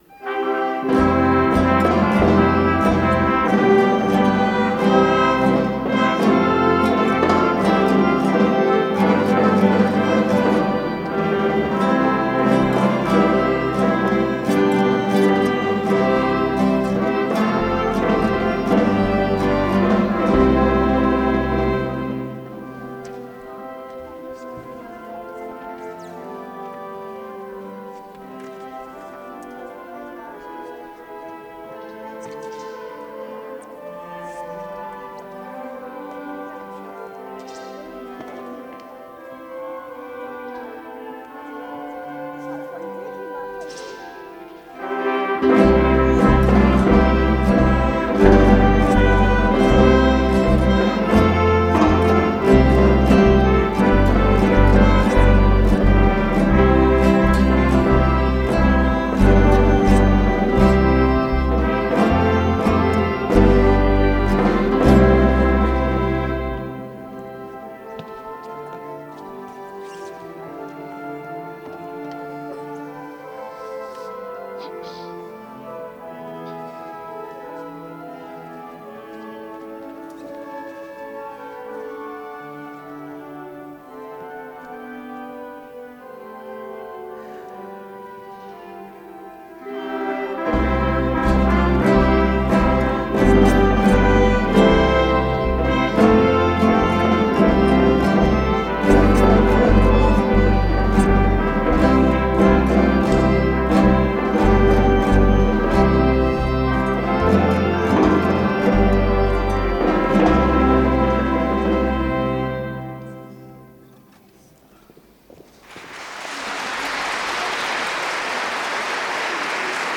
Kinderchor